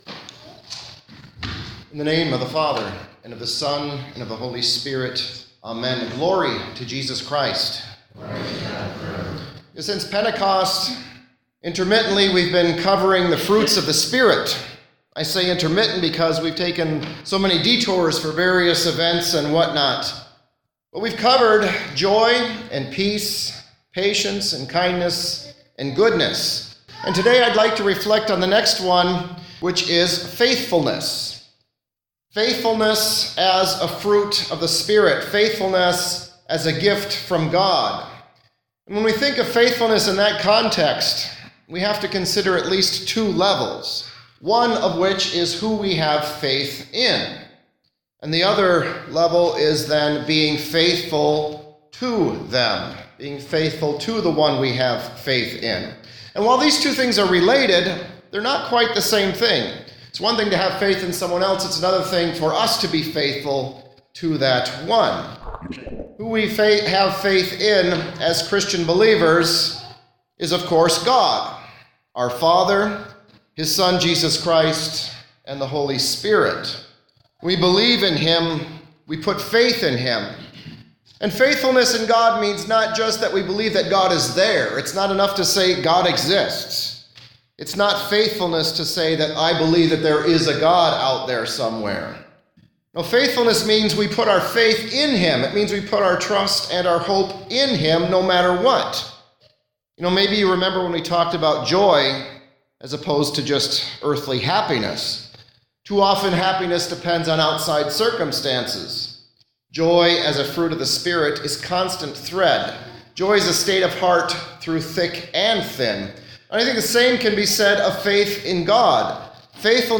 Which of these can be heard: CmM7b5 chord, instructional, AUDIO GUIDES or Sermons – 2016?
Sermons – 2016